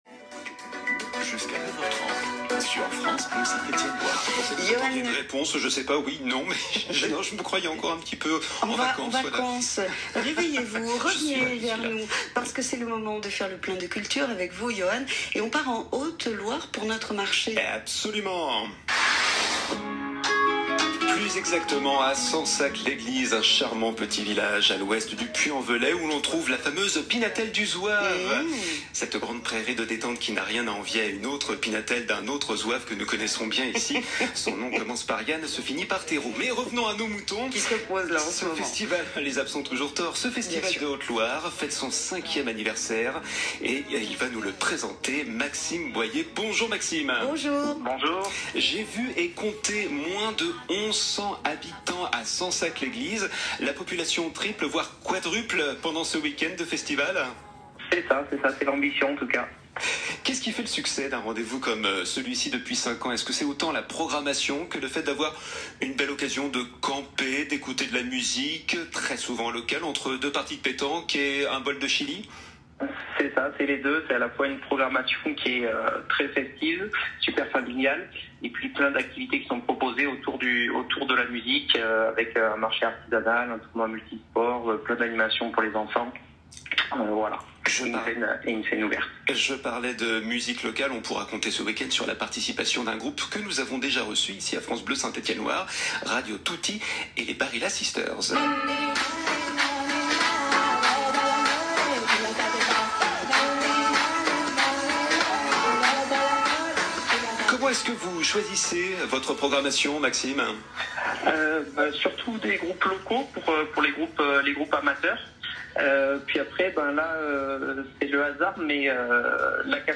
ITW_France_Bleu_Buena_Onda.mp3